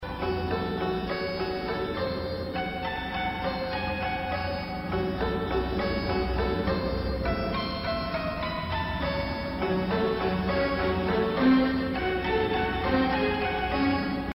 electric harpsicord!